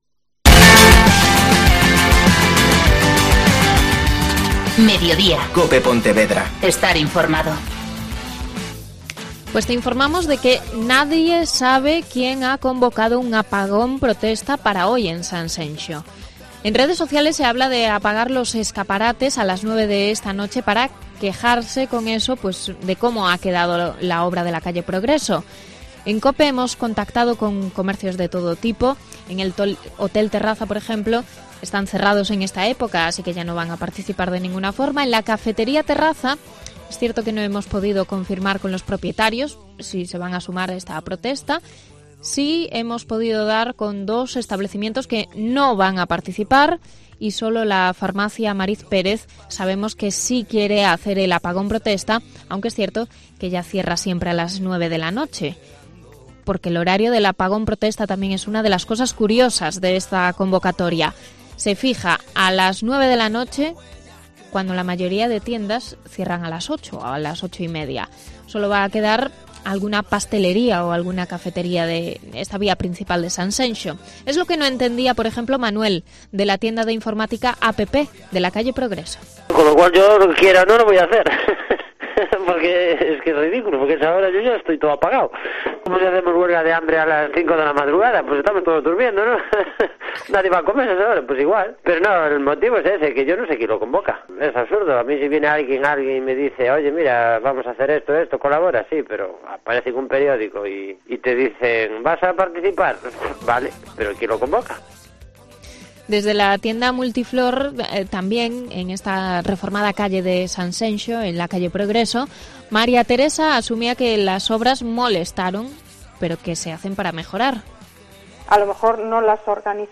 Varios profesionales de la calle Progreso opinan sobre la convocatoria de apagón